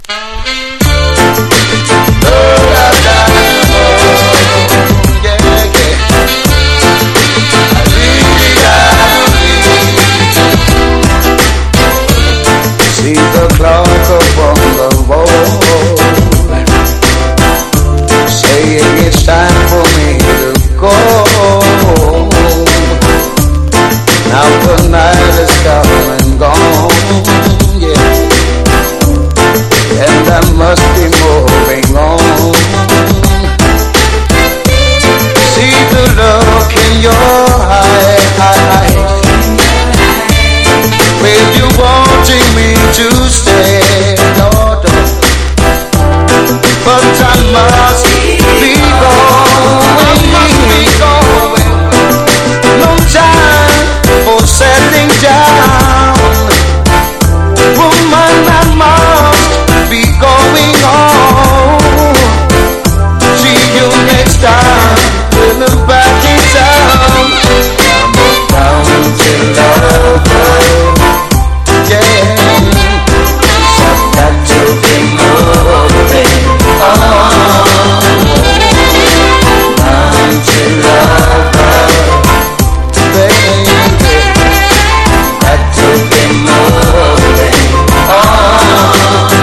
1. REGGAE >